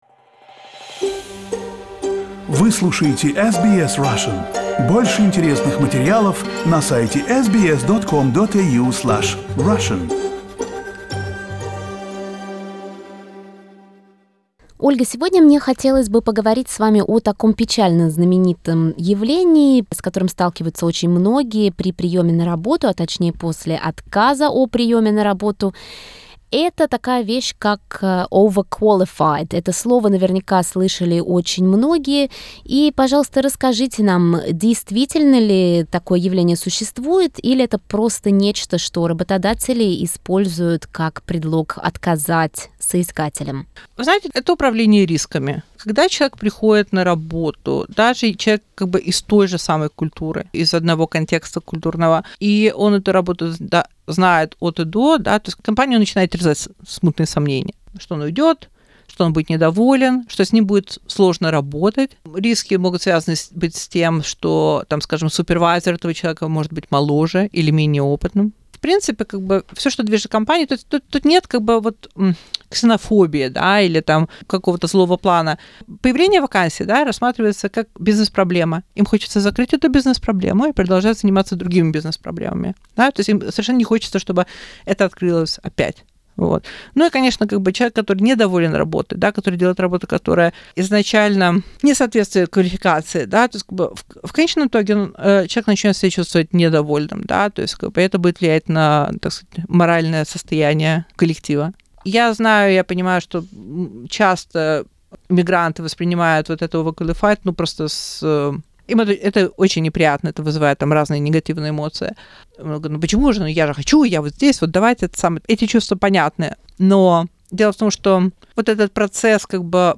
Очередная беседа